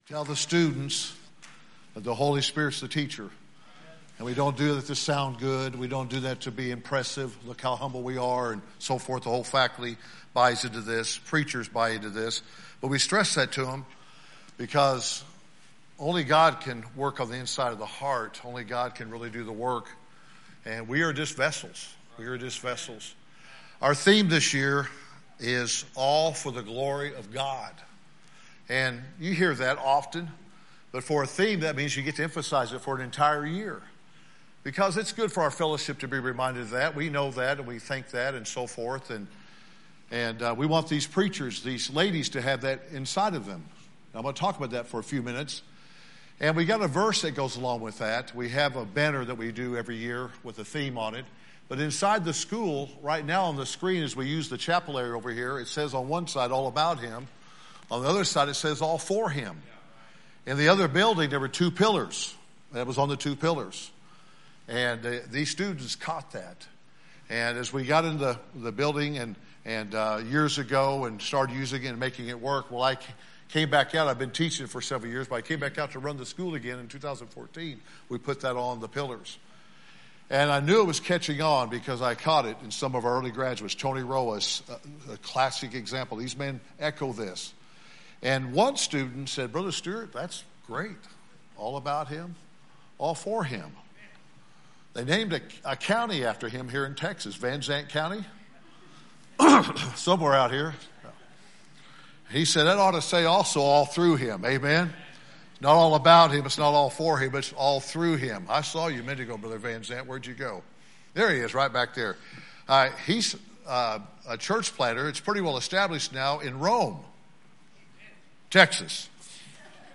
Forty-First Annual Commencement Exercises
Sermons